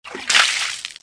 倒水.mp3